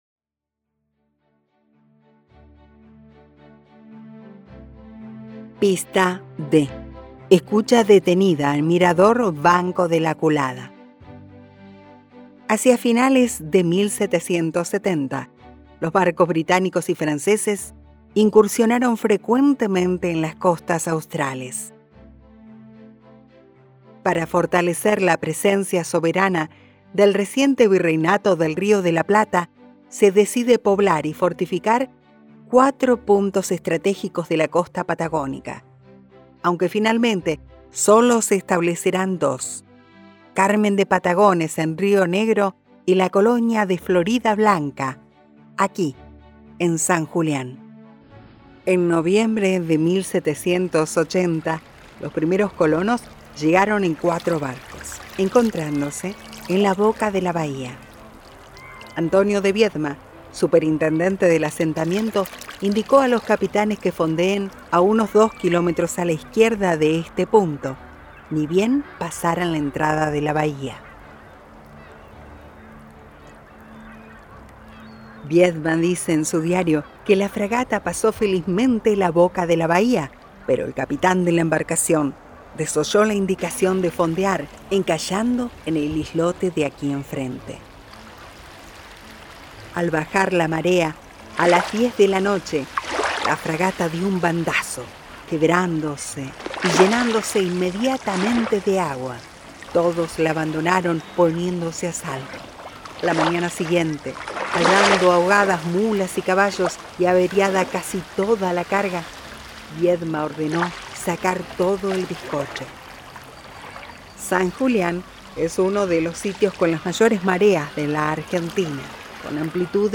Además, y para que esta propuesta sea más amena, incluimos también producciones musicales de nuestros artistas, que ilustran el paisaje y dan color al viaje.
Audioguía Vehicular Huelgas Patagónicas